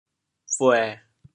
潮阳拼音 buê7
国际音标 [puε]